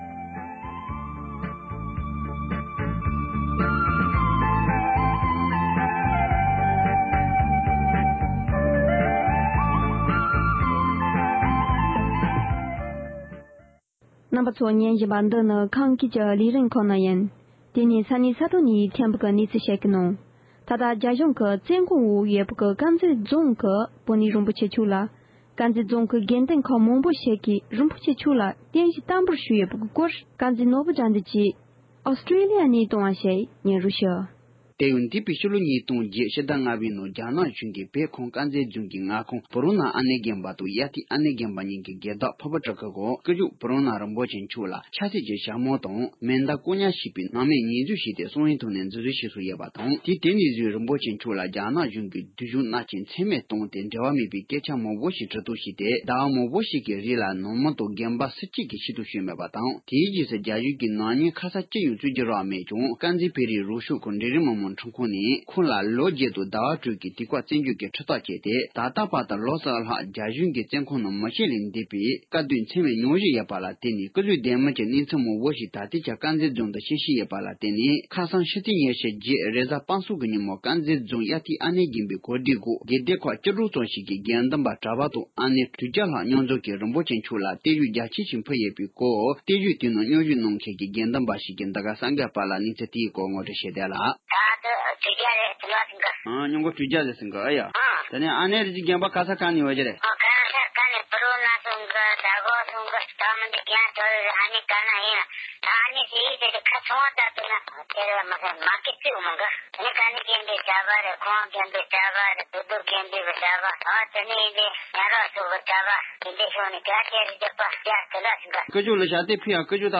སྒྲ་ལྡན་གསར་འགྱུར།
བརྟན་བཞུགས་ནང་མཉམ་ཞུགས་གནང་མཁན་གྱི་དགེ་འདུན་པ་ཞིག་གིས།